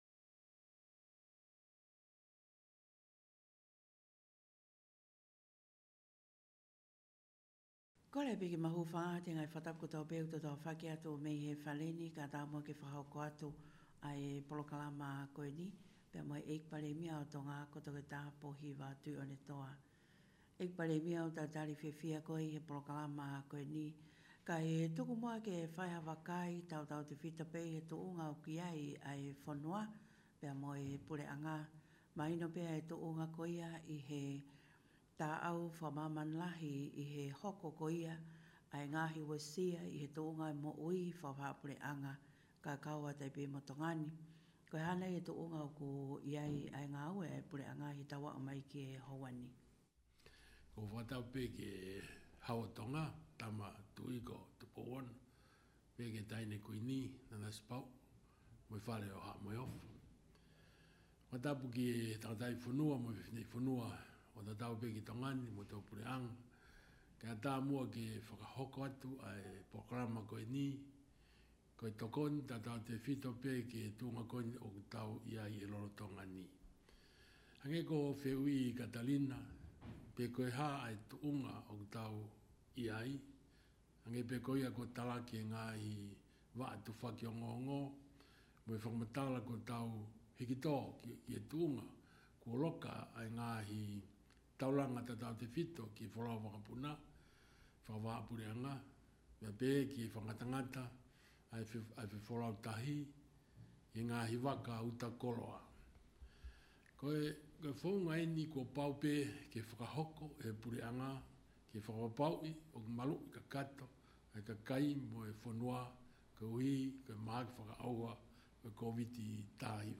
This is a translation of an audio recording provided by the office of Prime Minister Pōhiva Tu’i’onetoa.